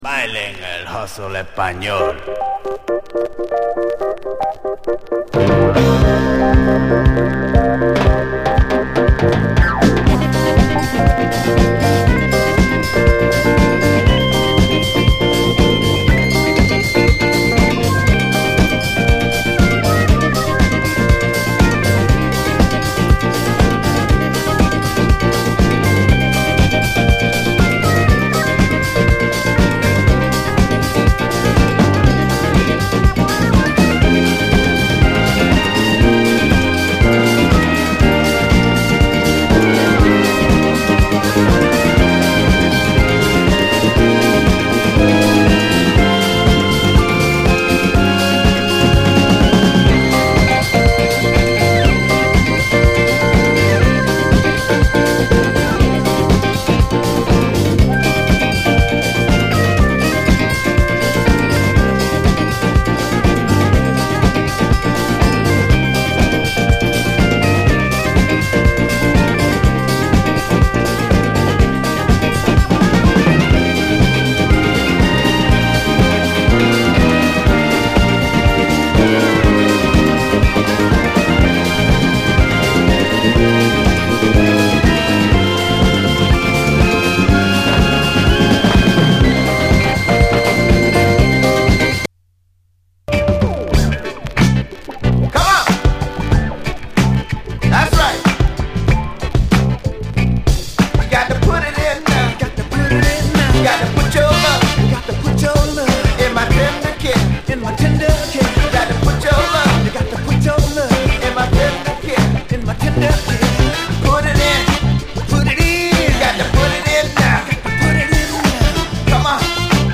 SOUL, 70's～ SOUL, DISCO, 7INCH
血沸き肉踊る、猥雑なラテン・ディスコ・クラシック！